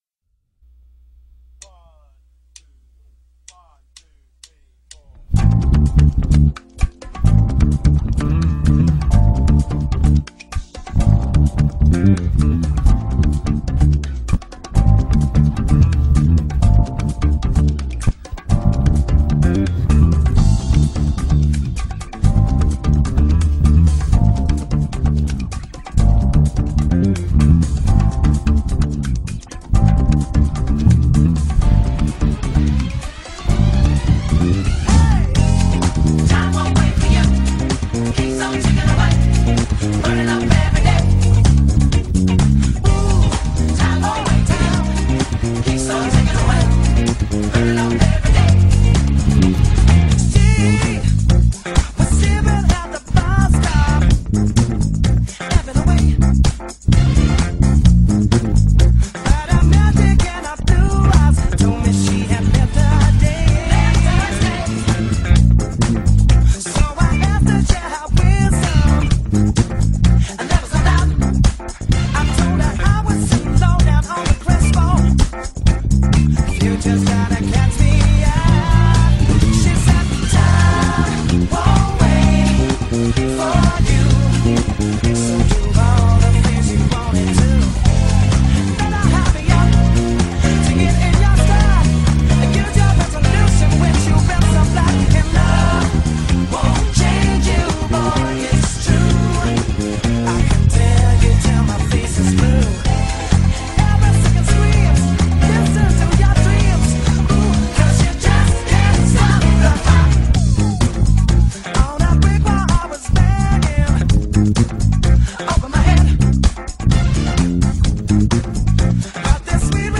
Algunos Covers:
Tocando el bajo de